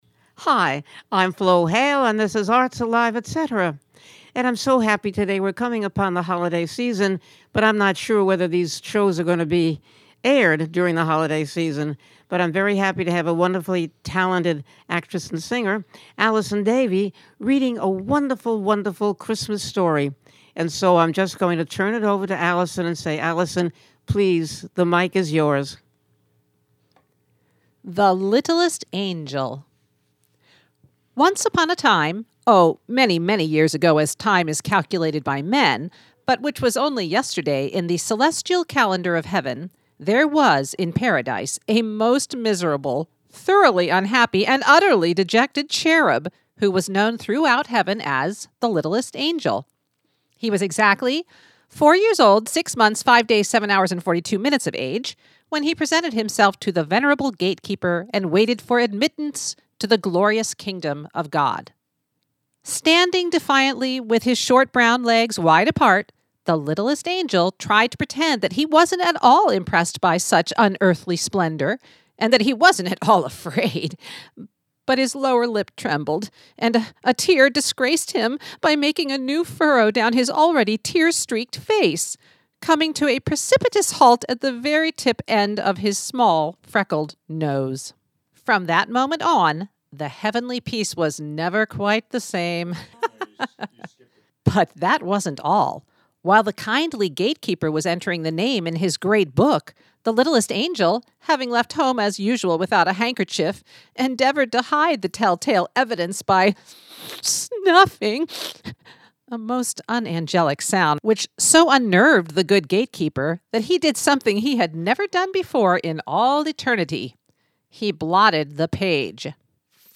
With a wicked wit and a true heart she banters with fellow singers and thespians, local luminaries and mover/shakers and knows how to get them to reveal what makes them tick.